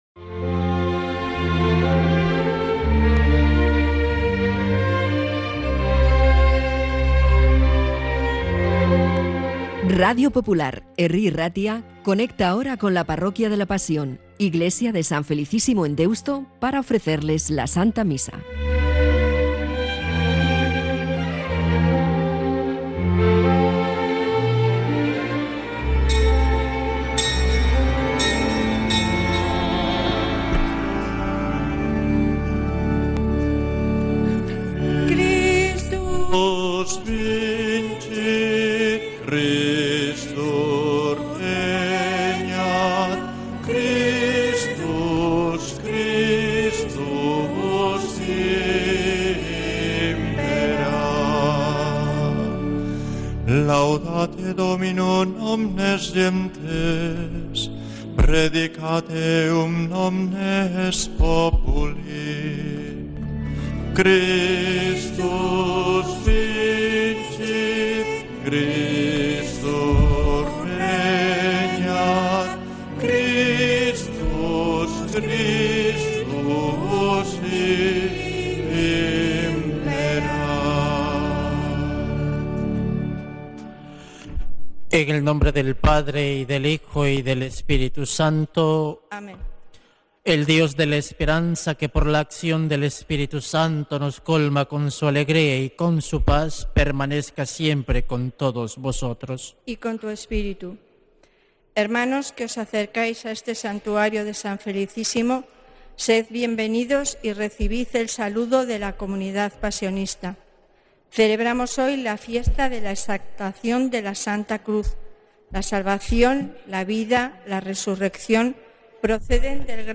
Santa Misa desde San Felicísimo en Deusto, domingo 14 de septiembre de 2025